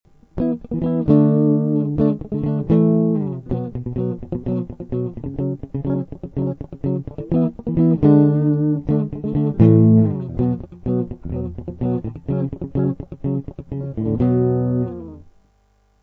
Проигрыш: